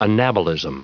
Prononciation du mot anabolism en anglais (fichier audio)
Prononciation du mot : anabolism